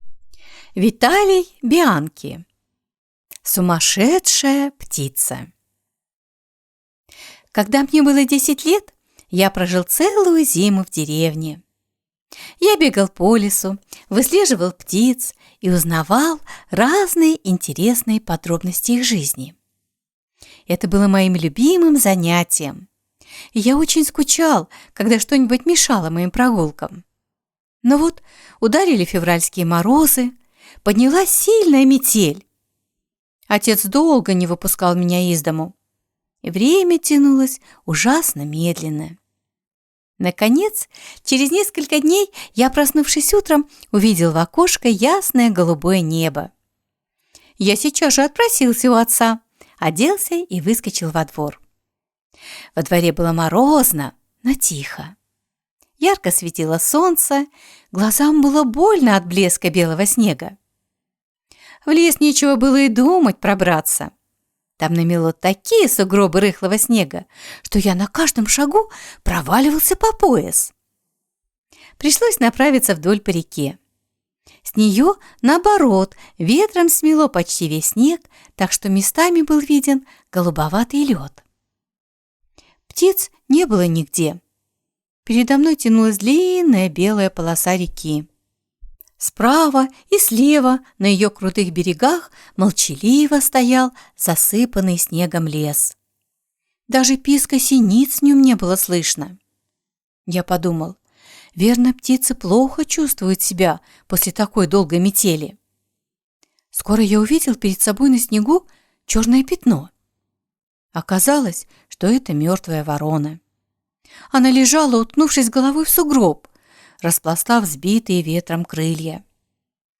Сумасшедшая птица - аудио рассказ Бианки - слушать онлайн